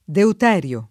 [ deut $ r L o ]